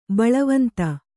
♪ baḷavanta